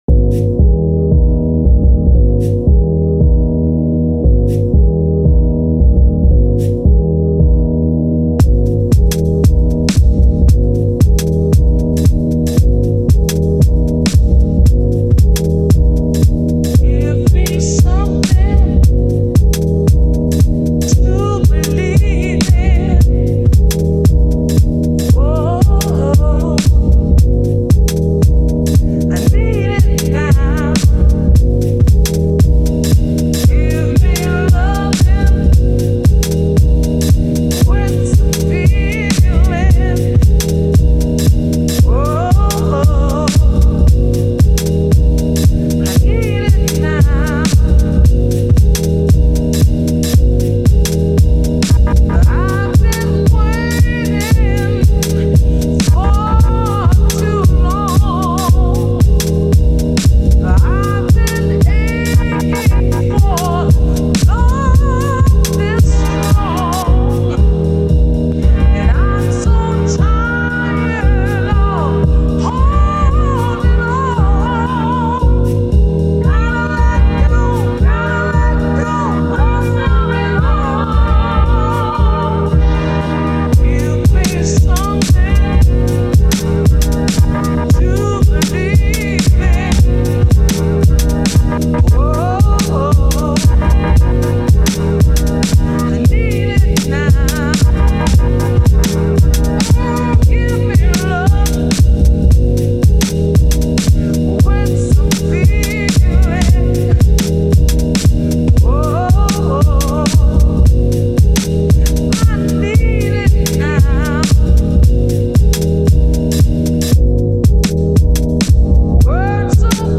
Here’s some house-ish tracks I’ve made over the years.
All Ableton & Serato Sample: